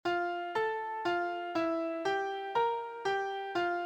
• Transposition shifts all the notes in a melody up or down by a specified number of steps.
Pitch class sequence F A F E G B♭ G F.